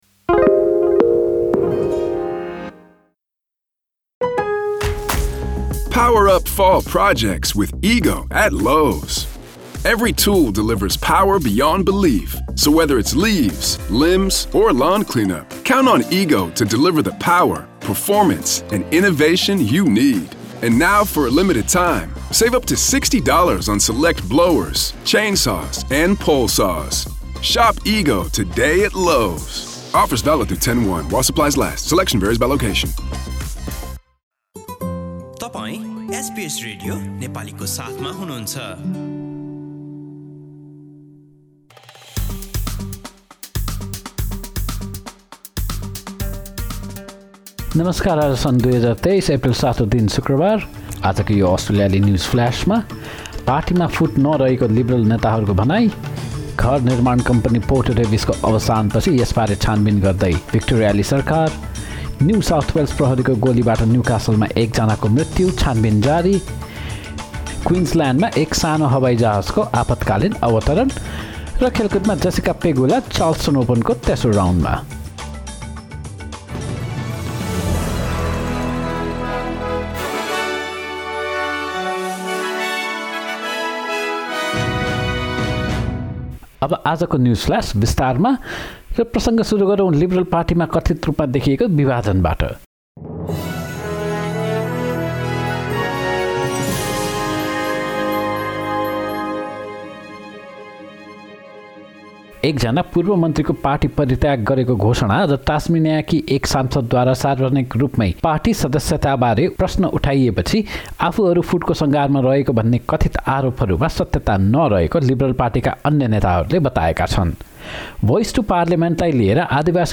एसबीएस नेपाली अस्ट्रेलिया न्युजफ्लास: शुक्रवार ७ एप्रिल २०२३